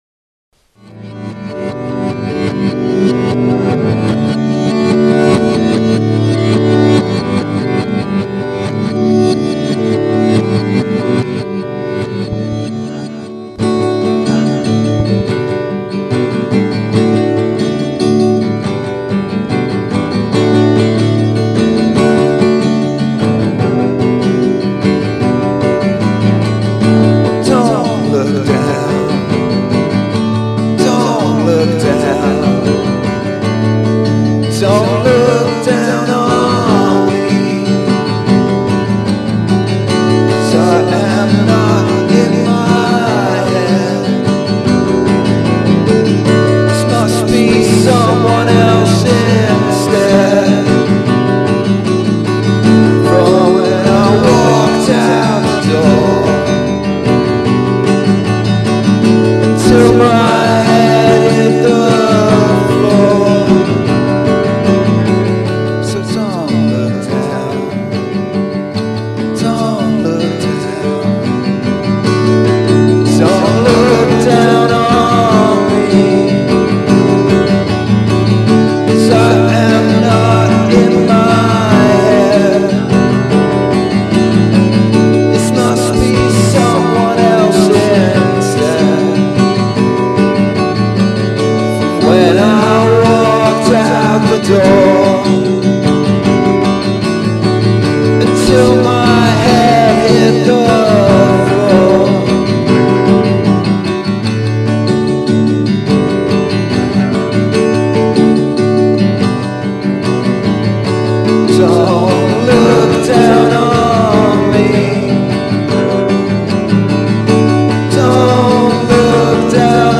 [] a bedroom in ipswich